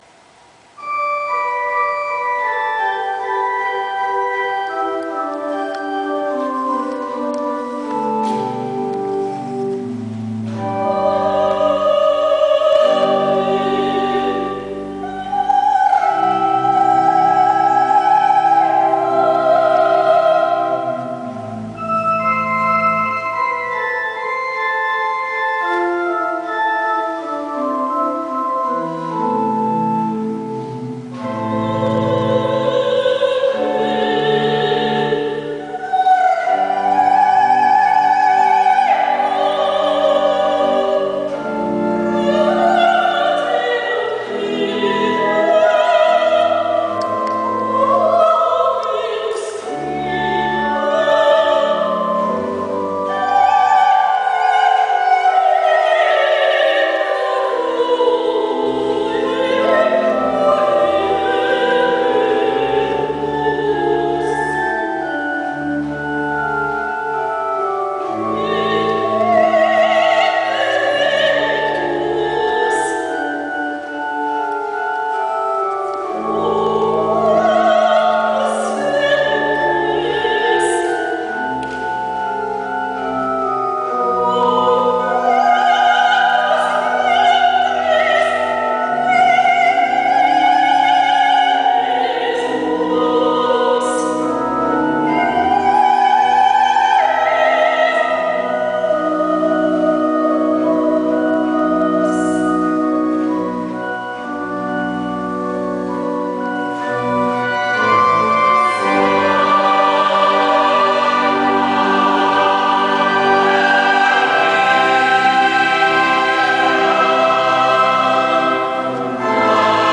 Aufnahme vom Konzert in Csongrád, 25.10.2009
orgonán játszik